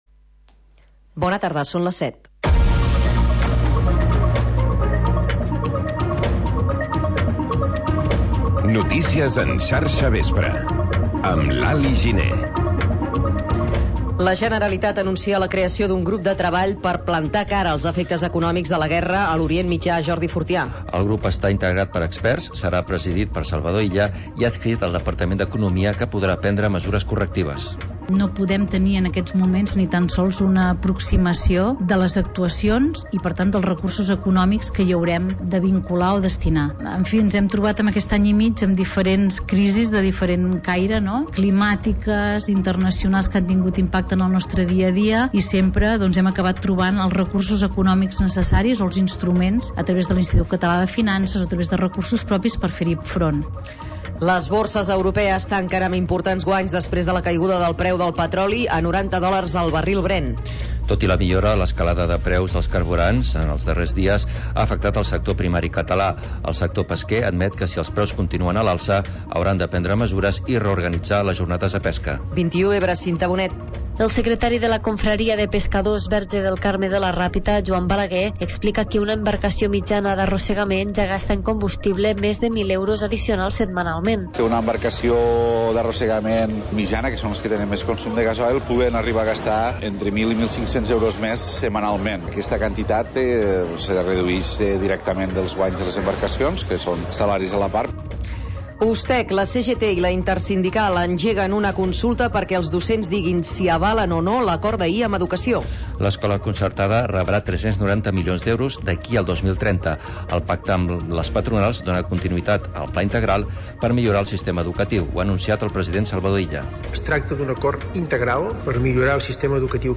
Informatiu territorial